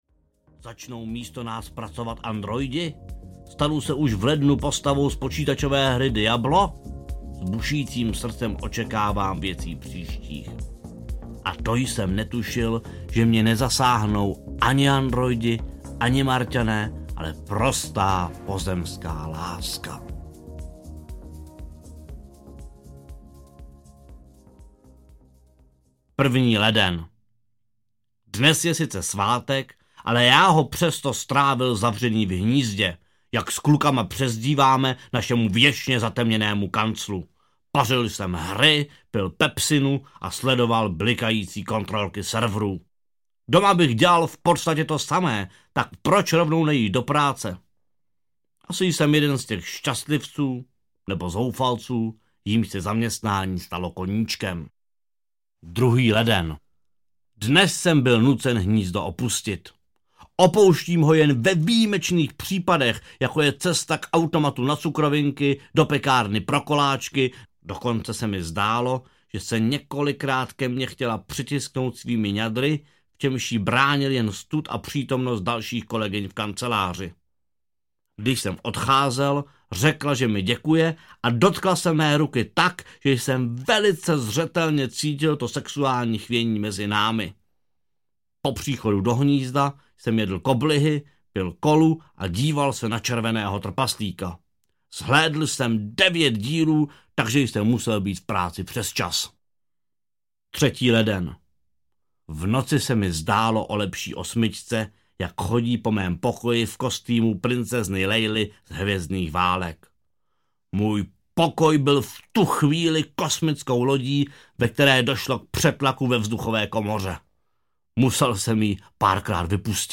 Z deníku ajťáka audiokniha
Ukázka z knihy
• InterpretLukáš Pavlásek